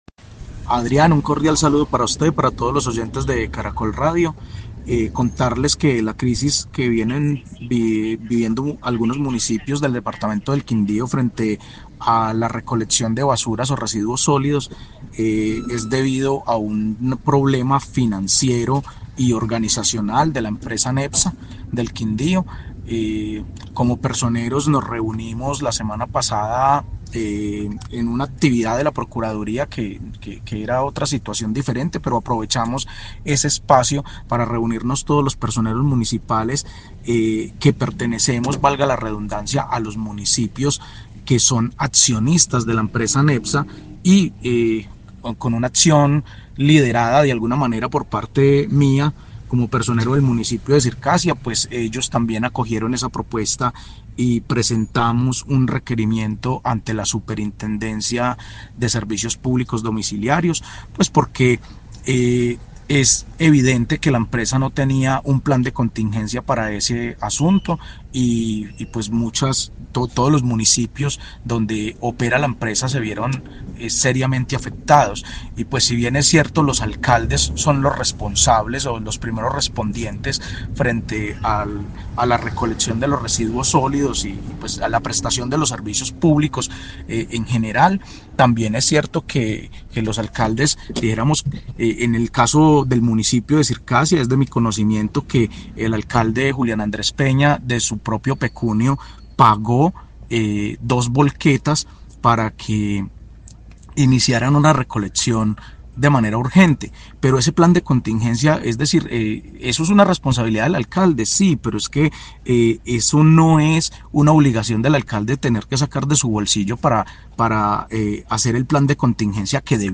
José Ariel Cardona, personero de Circasia